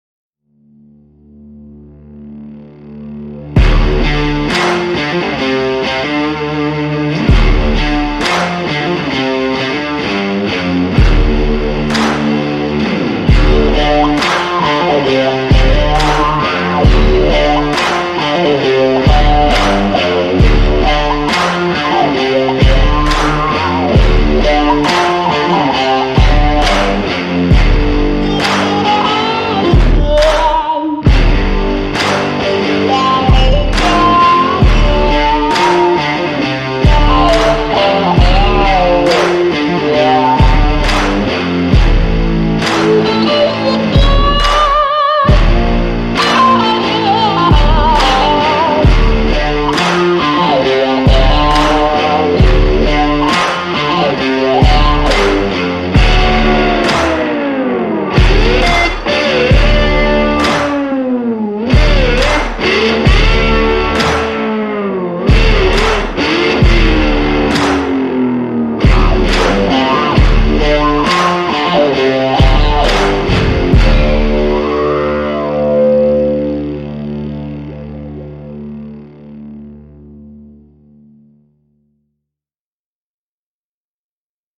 L’atmosphère, résolument rock et déglinguée